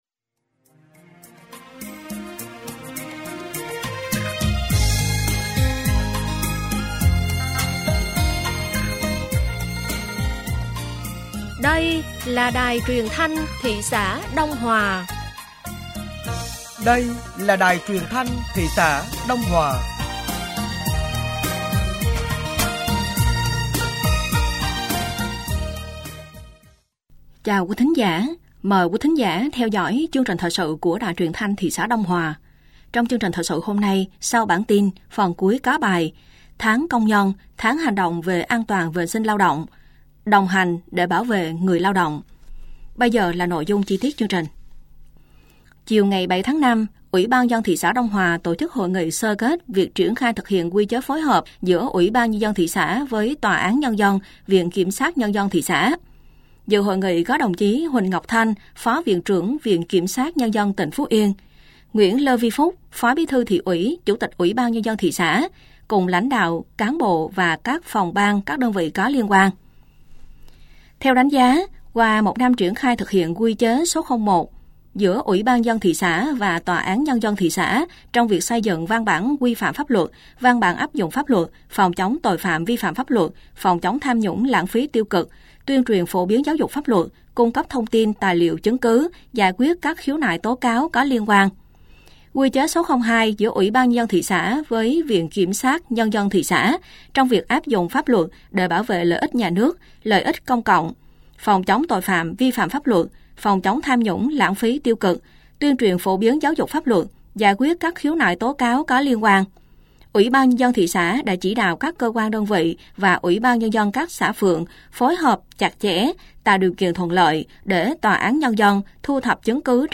Thời sự tối ngày 08 và sáng ngày 09 tháng 5 năm 2025